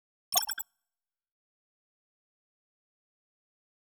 Futurisitc UI Sound 19.wav